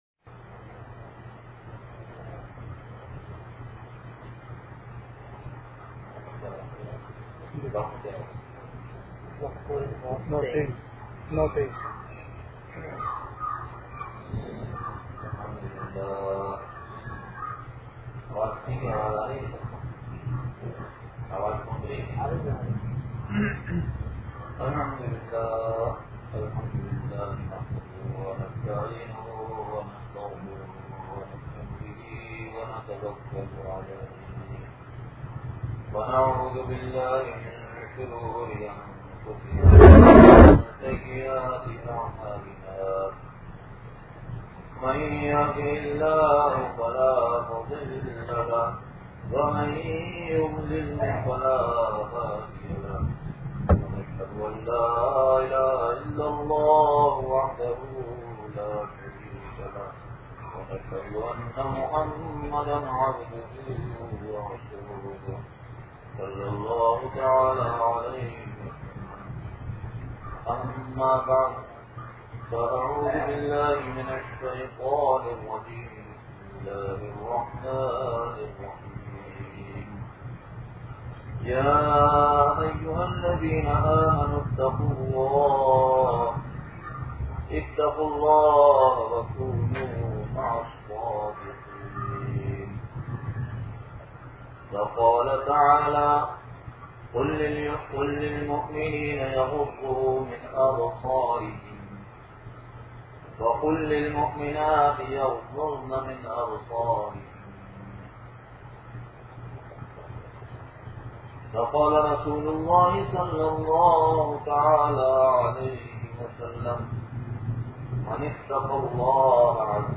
بیان بعد نماز عشا ٗ جامع بابِ رحمت مسجد منوا آباد نوابشاہ سندھ